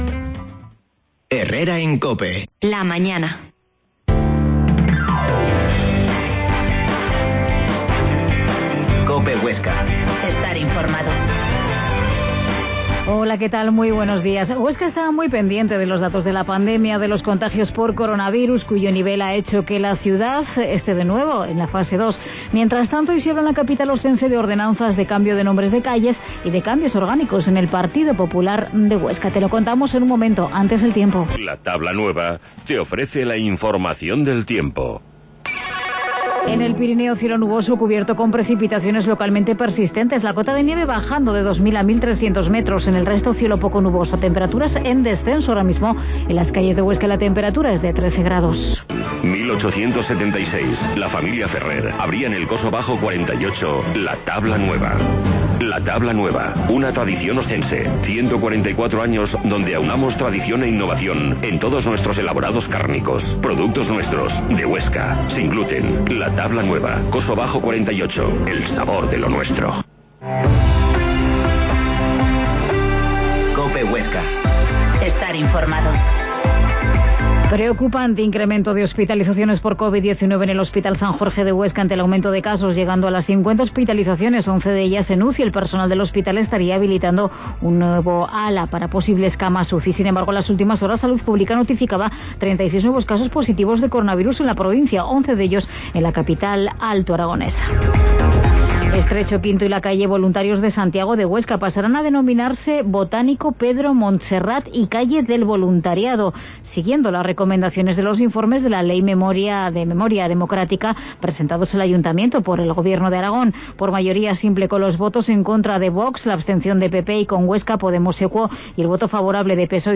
Herrera en COPE Huesca 12.50h Entrevista al Presidente del PP en Huesca, José Antonio Lagüens